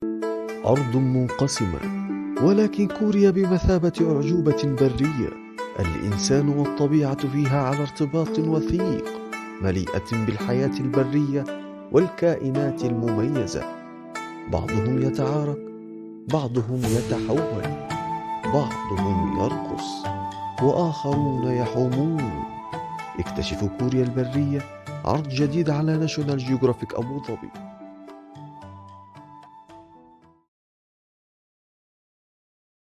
专题配音【生态农业】